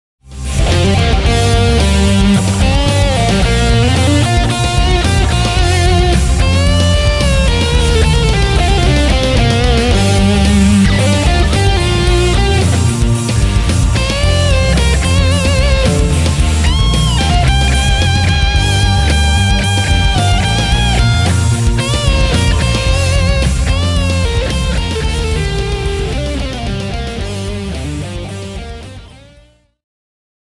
Tässä on kolme lyhyttä pätkää, joissa soitan soolo-osuudet JS-10:n omien eBand-biisien päälle:
Boss JS-10 – Gothic Metal